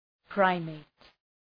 Προφορά
{‘praımıt}